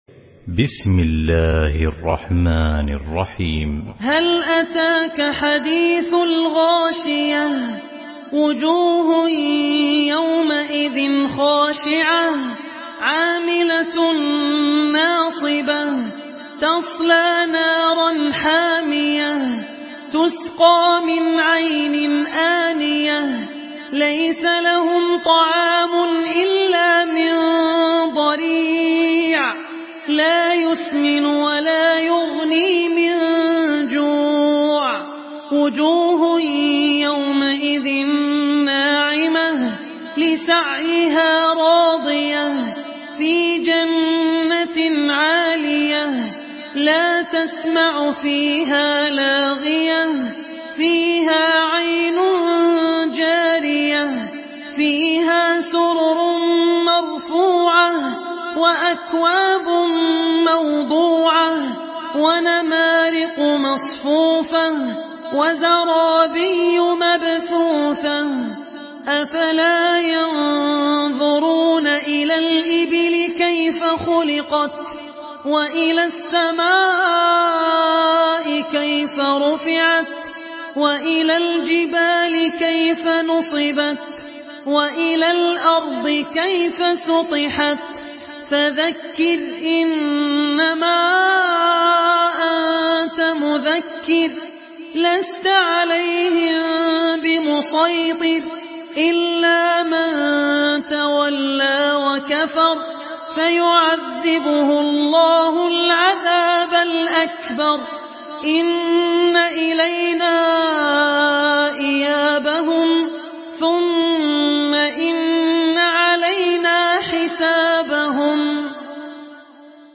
Surah Gaşiye MP3 by Abdul Rahman Al Ossi in Hafs An Asim narration.
Murattal